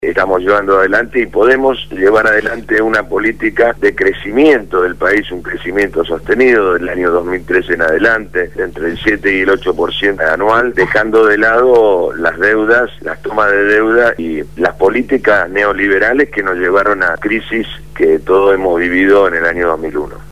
Francisco «Tito» Nenna, Legislador Porteño por el Frente Para la Victoria, habló en el programa Punto de Partida (Lunes a viernes de 7 a 9 de la mañana) de Radio Gráfica FM 89.3 sobre la decisión de la Legislatura de endeudar -a pedido del Jefe de Gobierno- a la Ciudad por 500 millones de dólares.